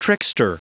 Prononciation du mot trickster en anglais (fichier audio)
Prononciation du mot : trickster